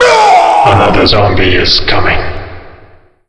zombie_coming_03.wav